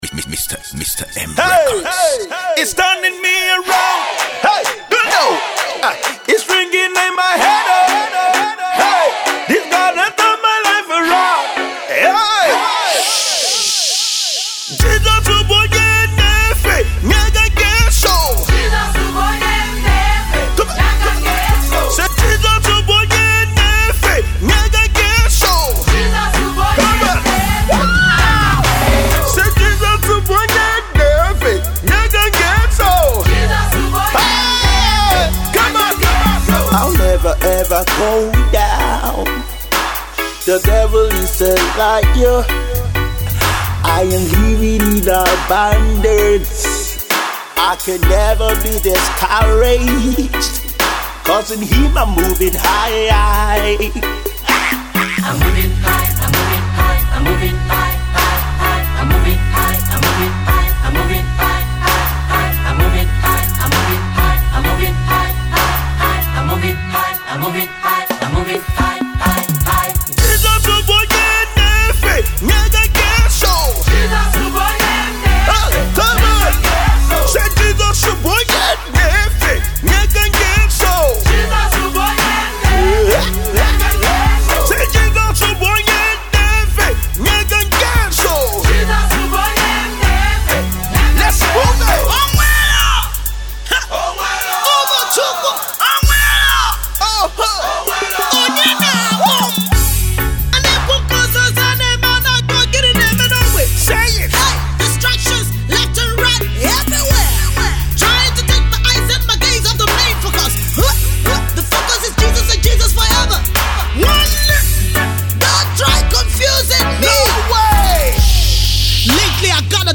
a gospel musical group from South-East of Nigeria.
It’s a powerful praise song is a praise declaration.